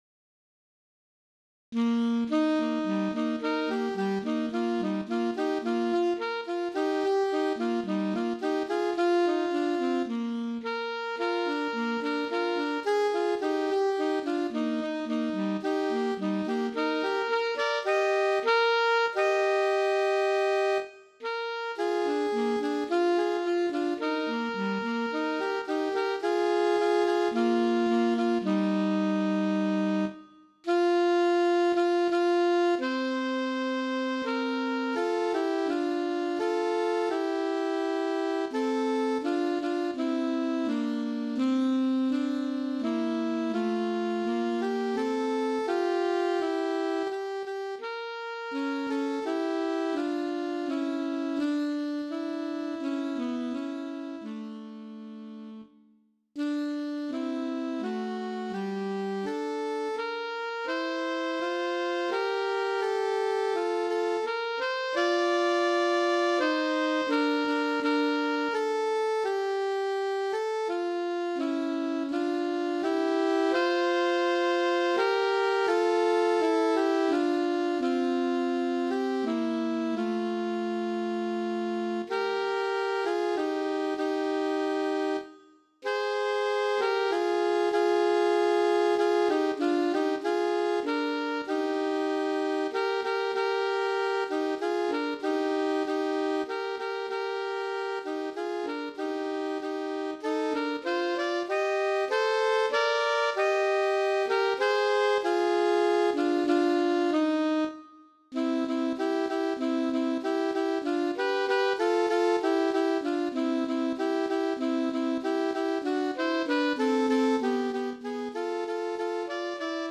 2 Alto Saxophones